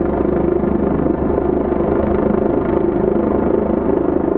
main_rotor_idle_power_blade_stall.wav